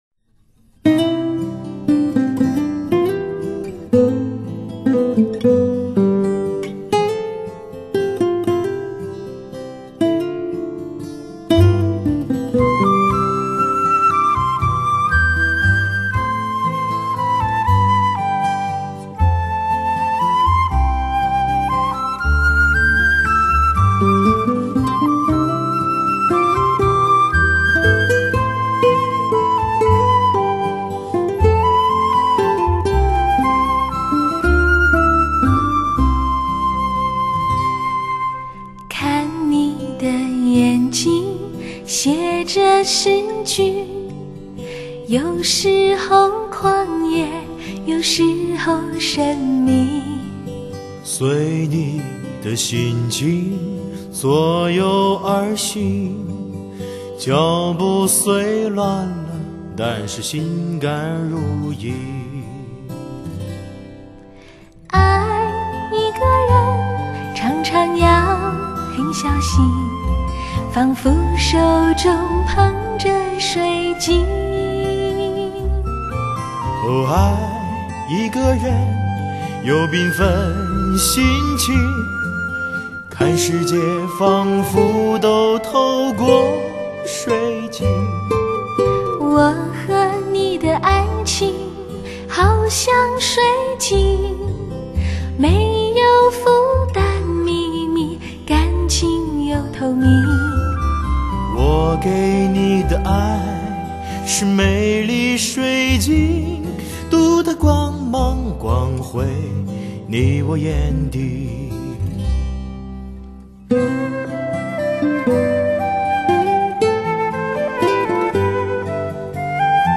采用“DSD直接刻录”技术 弦乐四重奏
钢琴